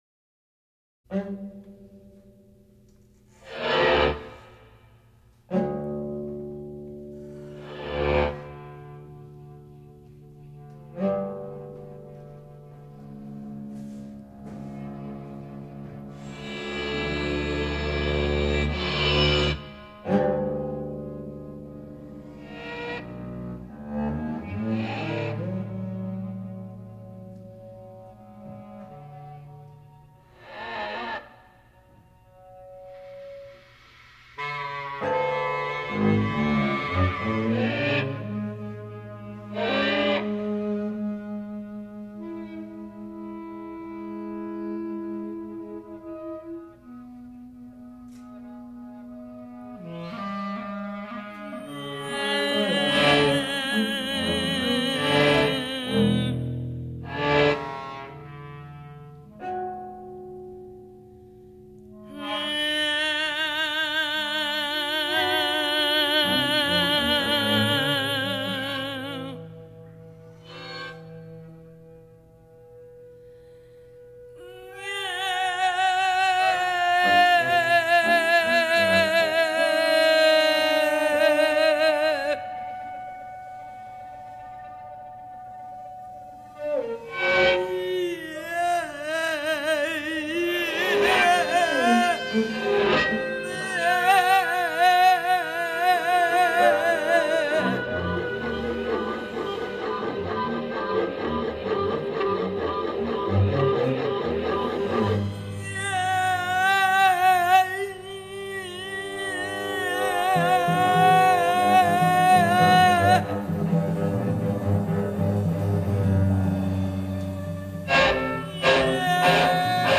vocals
clarinets
piano
electric guitar
bass
percussion